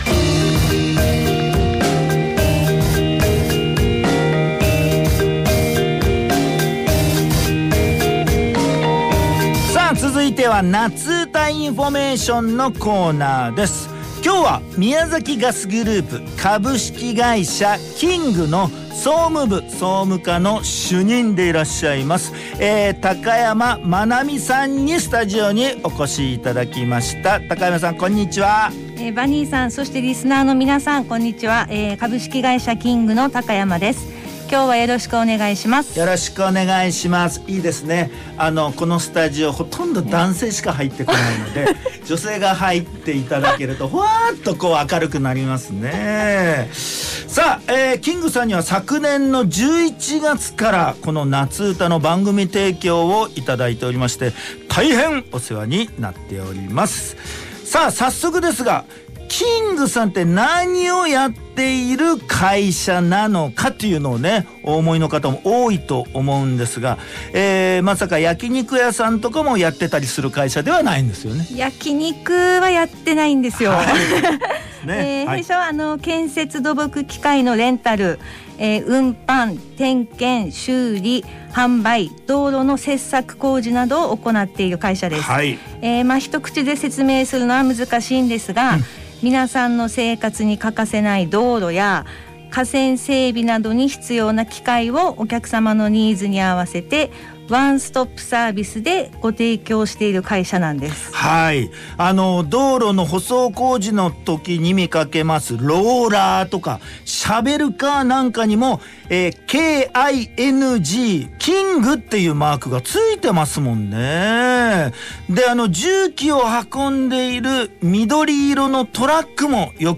エフエム宮崎で弊社の企業紹介が放送されました。